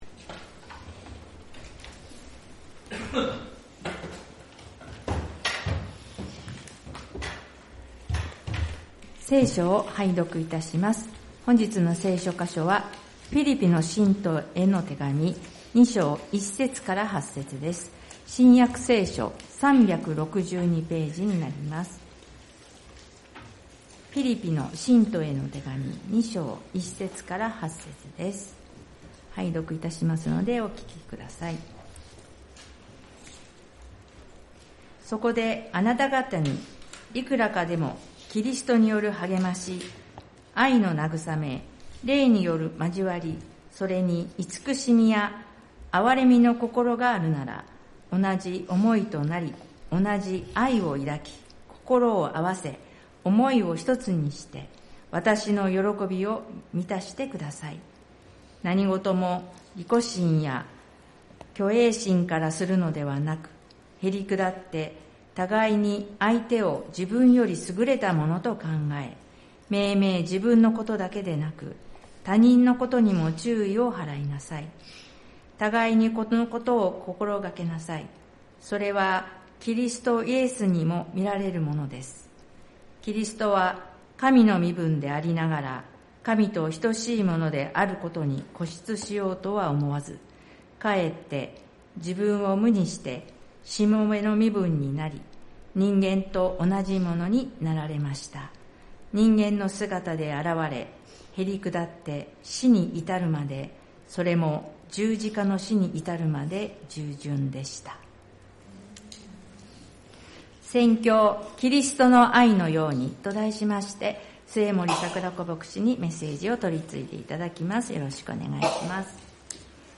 聖日礼拝「キリストのように」